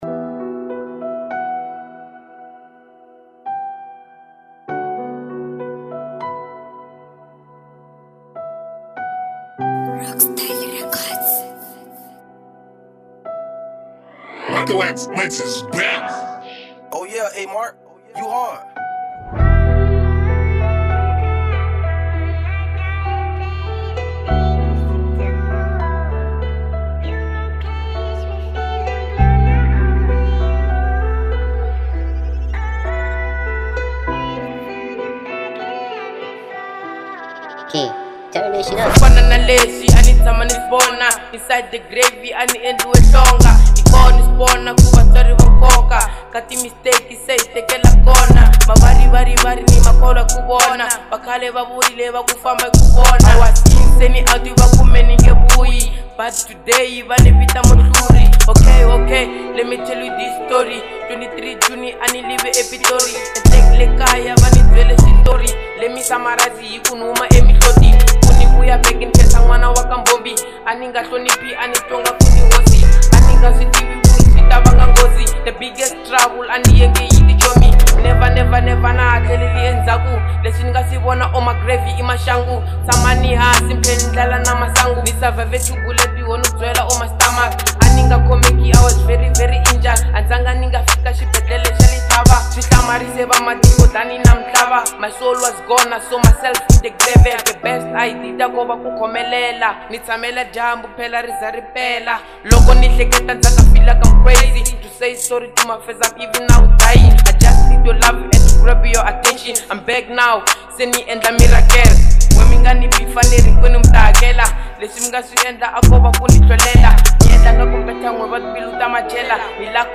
03:35 Genre : Hip Hop Size